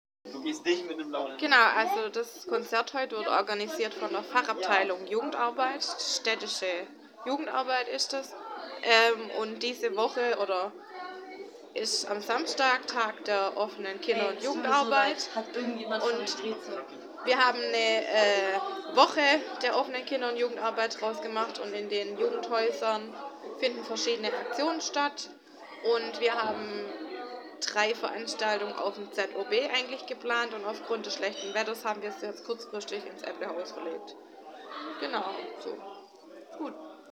Interview_Woche_der_Jugendarbeit-_Tuebingen-zum_Konzert_26924_22-16-21.mp3